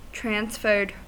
Ääntäminen
IPA : /tɹɑːnsˈfɜːd/ IPA : /tɹænsˈfɜːd/